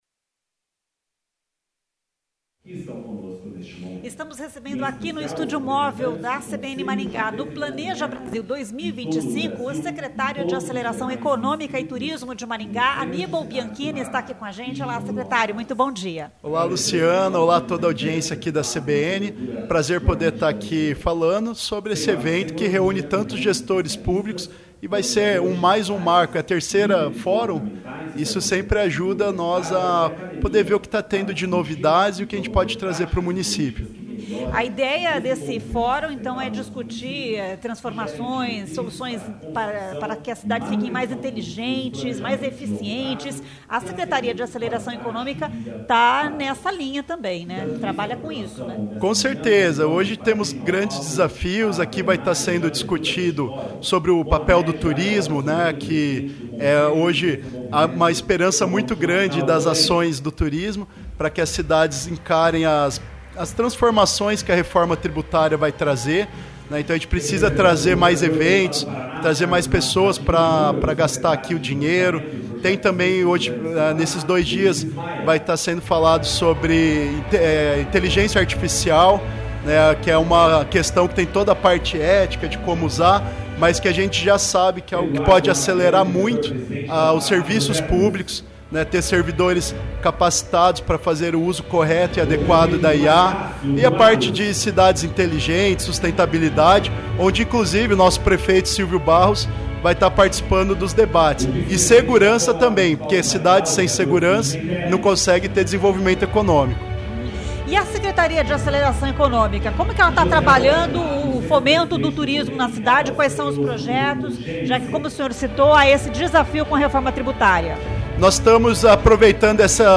Annibal Bianchini, Secretário de Aceleração Econômica e Turismo, em entrevista à CBN Maringá.
A entrevista foi realizada no estúdio móvel CBN instalado no local do evento.